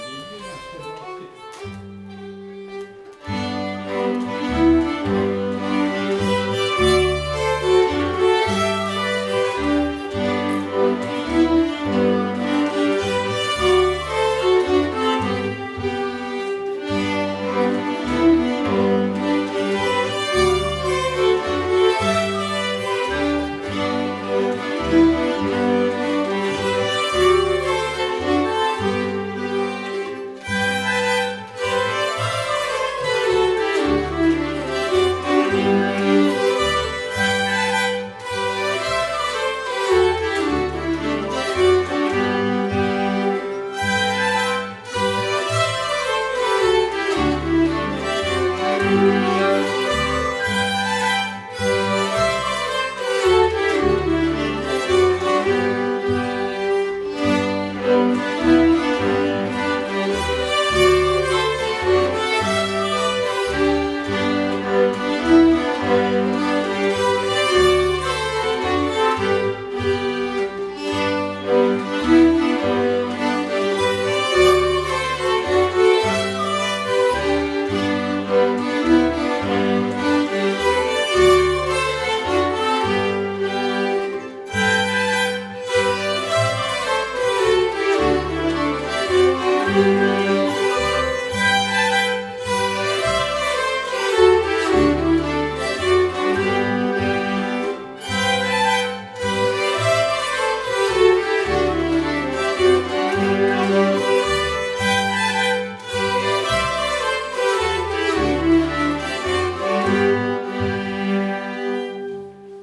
Spelmansstämma!
Bland många roliga inslag ingår ju även ett allspel!
Hambo av Bror Dahlgren (